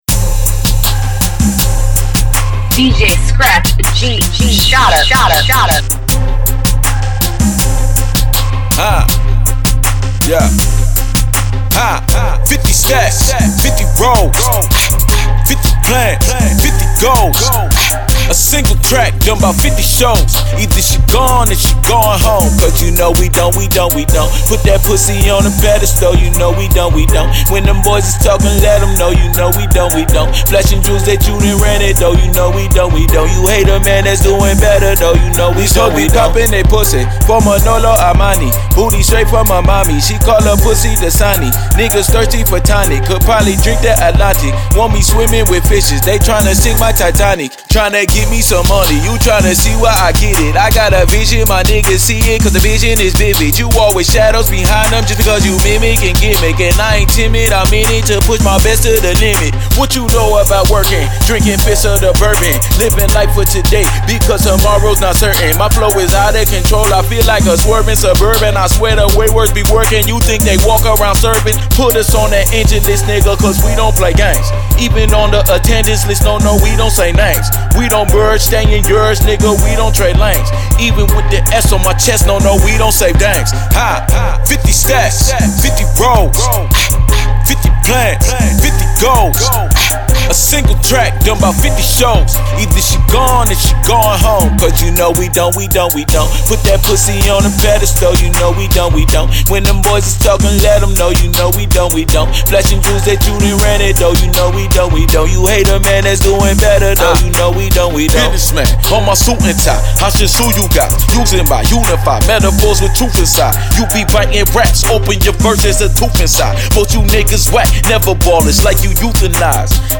Hiphop
Club Banger